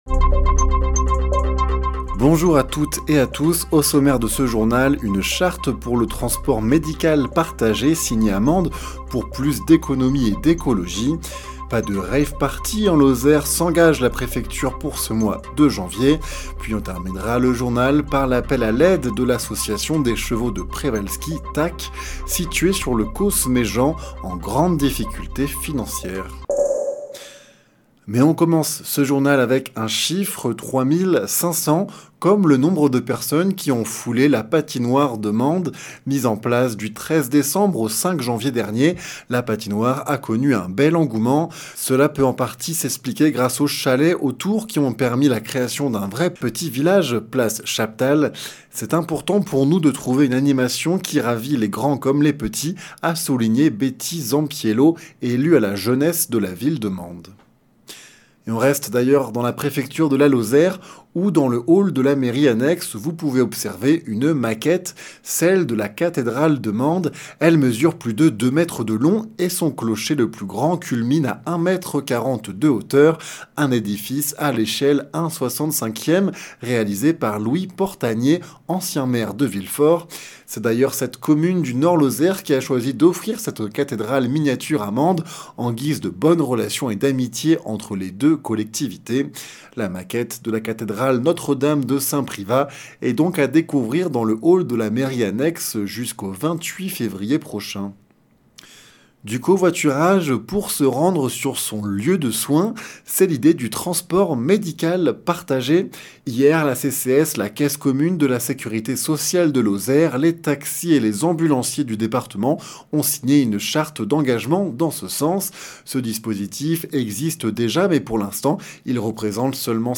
Le journal sur 48FM